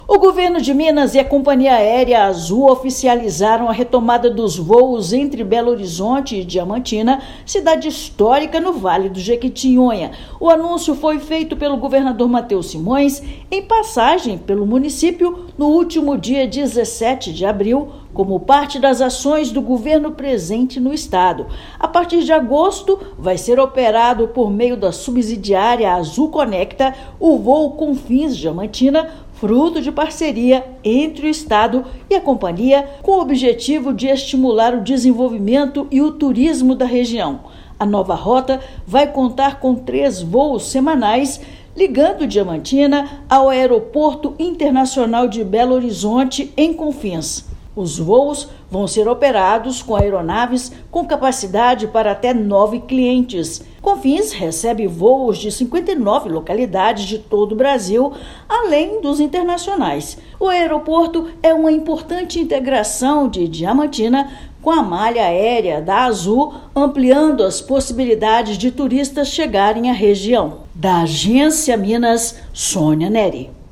[RÁDIO] Governo de Minas e Azul oficializam parceria para retomada de voos entre Belo Horizonte e Diamantina
Anúncio foi feito pelo governador Mateus Simões na cidade histórica; operação será iniciada em agosto, com três voos semanais a partir do Aeroporto de Confins. Ouça matéria de rádio.